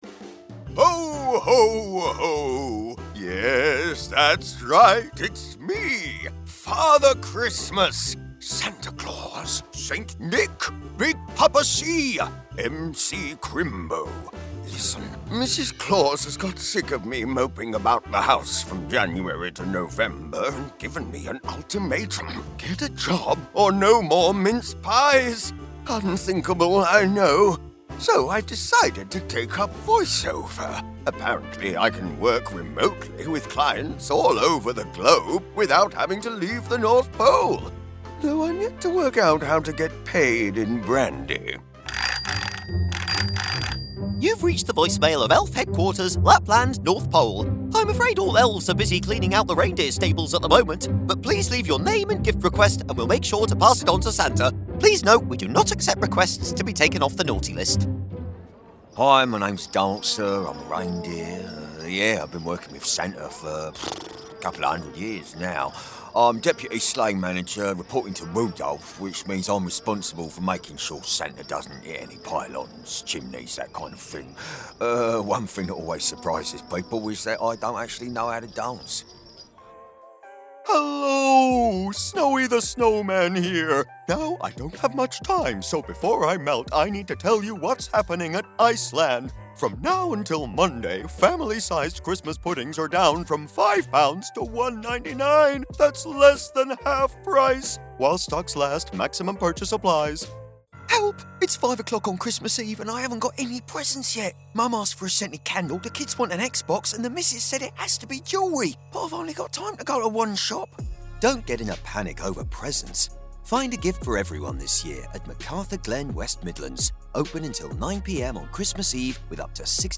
Male
Assured, Authoritative, Confident, Corporate, Deep, Engaging, Gravitas, Posh, Reassuring, Smooth, Warm, Witty
Microphone: Sontronics Aria Cardioid Condenser Valve Mic, Shure SM7B